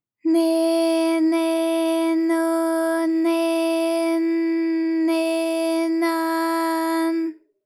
ALYS-DB-001-JPN - First Japanese UTAU vocal library of ALYS.
ne_ne_no_ne_n_ne_na_n.wav